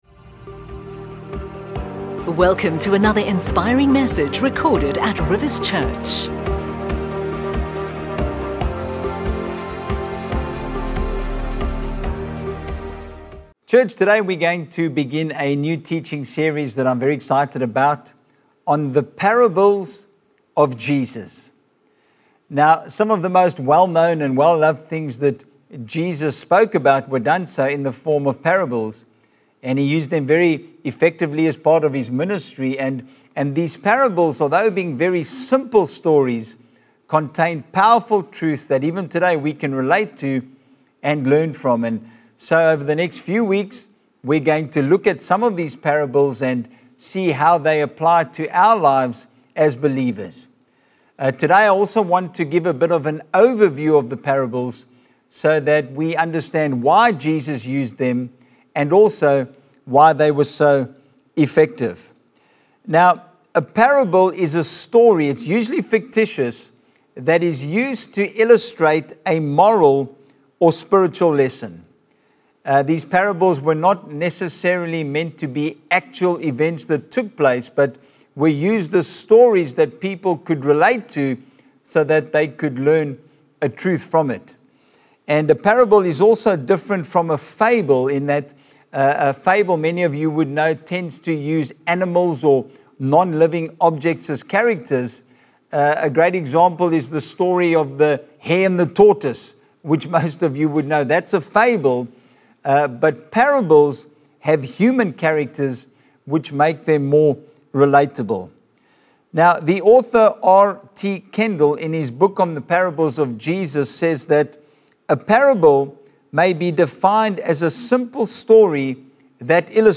You can download our weekend messages for free!